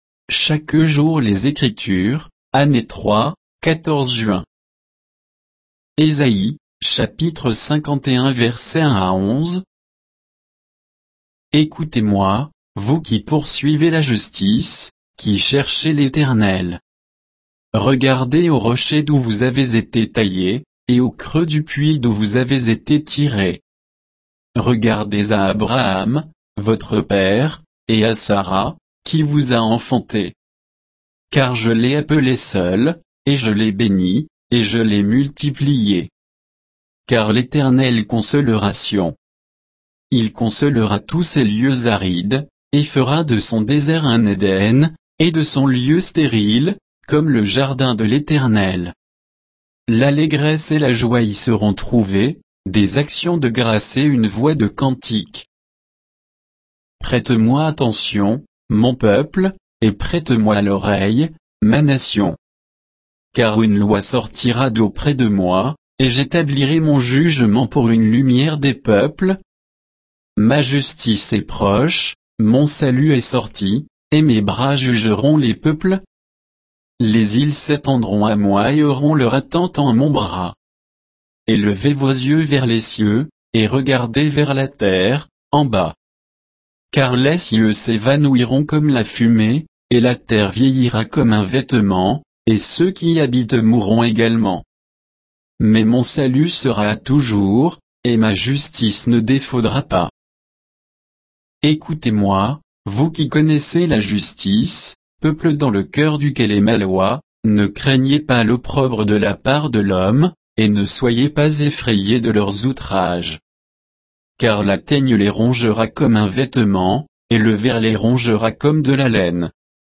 Méditation quoditienne de Chaque jour les Écritures sur Ésaïe 51, 1 à 11